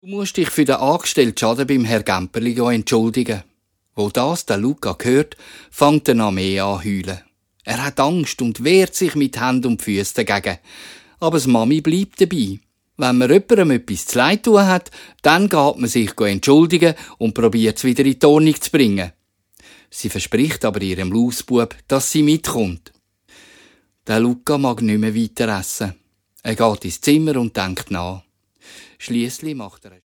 Hörspiel-Album (ohne Kalender)